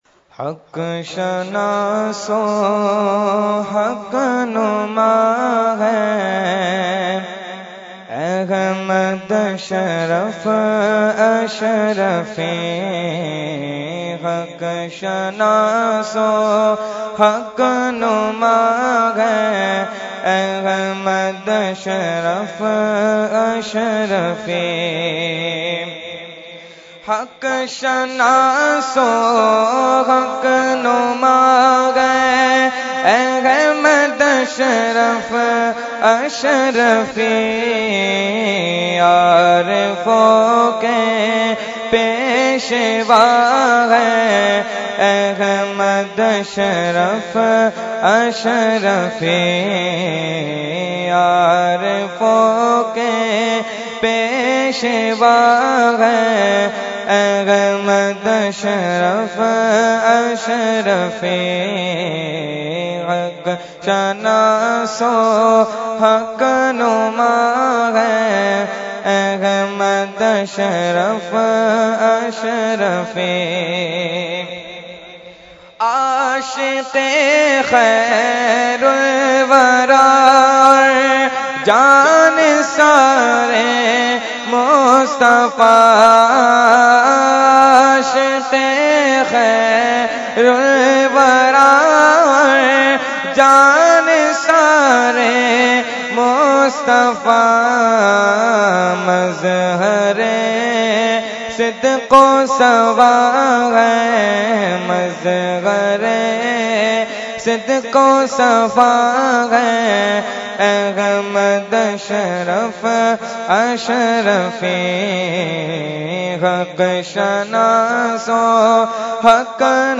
Category : Manqabat | Language : UrduEvent : Urs Ashraful Mashaikh 2017